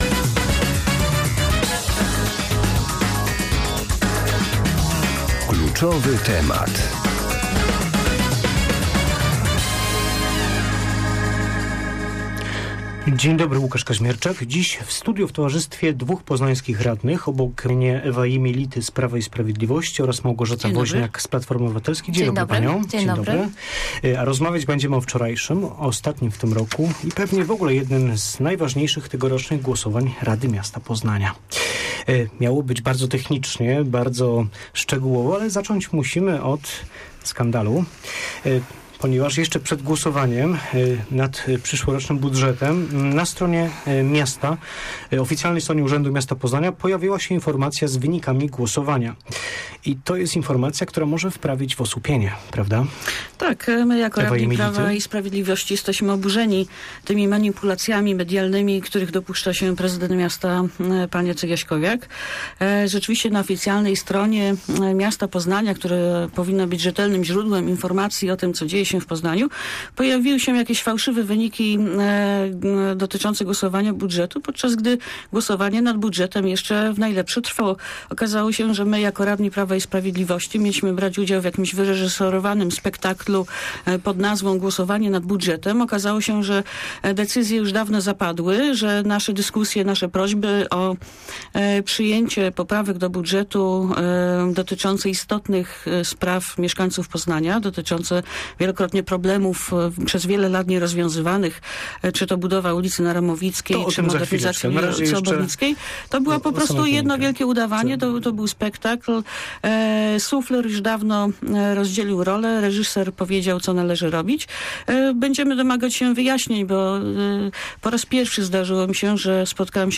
Sufler rozdzielił wcześniej rolę, a reżyser powiedział co należy robić" - uważa poznańska radna Prawa i Sprawiedliwości Ewa Jemielity komentując w porannej rozmowie Radia Merkury wczorajszą informację o tym, że jeszcze przed głosowaniem nad przyszłorocznym budżetem miasta na oficjalnej stronie Urzędu Miasta Poznania pojawiły się jego wyniki.